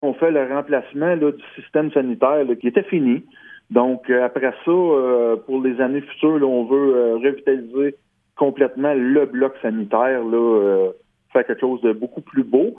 Le maire de Gracefield, Mathieu Caron, commente :